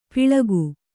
♪ piḷagu